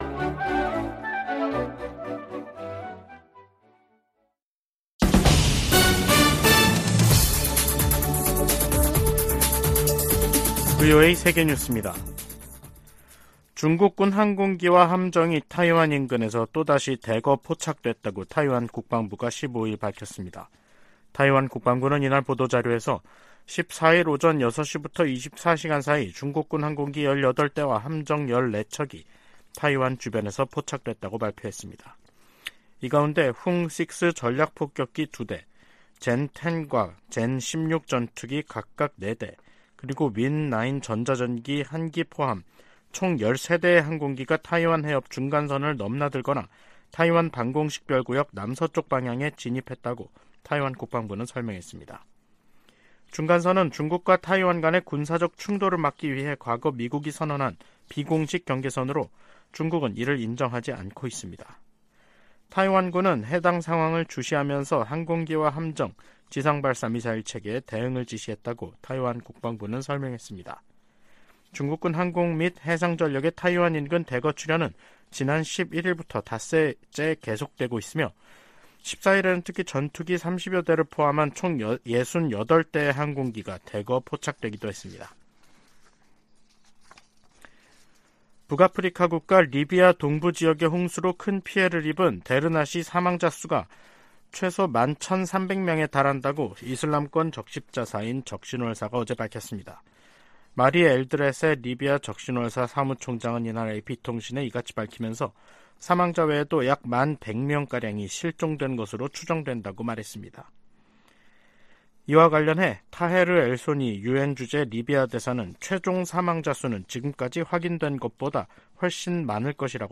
VOA 한국어 간판 뉴스 프로그램 '뉴스 투데이', 2023년 9월 15일 3부 방송입니다. 미국과 한국은 유엔 안보리 결의에 부합하지 않는 북한과 러시아 간 협력에 강력한 우려를 표명한다고 밝혔습니다. 미 국방부는 북한과 러시아 간 추가 무기 거래 가능성과 관련, 민간인 학살에 사용되는 무기를 제공해선 안 된다고 거듭 강조했습니다. 미국 정부가 한국에 대한 50억 달러 상당 F-35 스텔스 전투기 25대 판매를 승인했습니다.